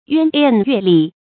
淵渟岳立 注音： ㄧㄨㄢ ㄊㄧㄥˊ ㄩㄝˋ ㄌㄧˋ 讀音讀法： 意思解釋： 見「淵渟岳峙」。